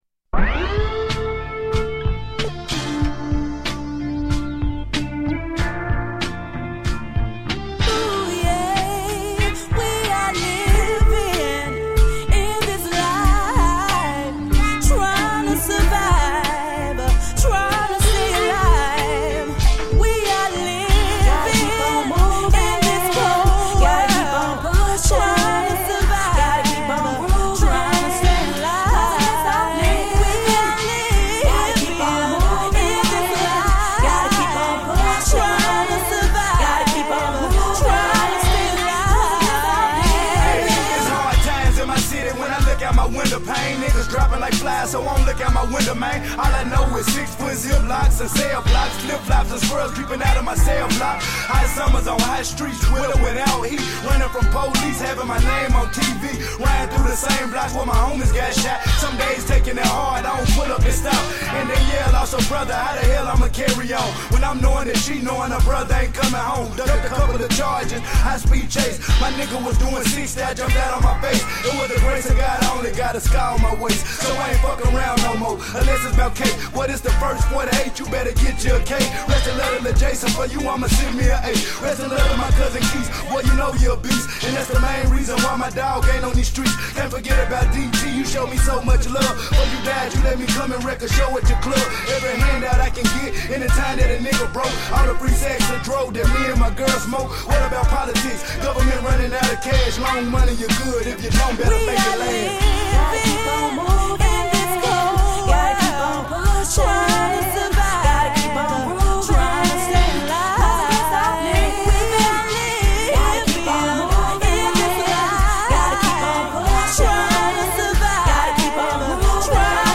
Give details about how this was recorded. The Onyoradiodial Show is a live talk show based on topics ranging from new niche's in the industry to open discussions. The show provides a discussion platform setting about the industry, the old and the new.